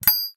clicking.ogg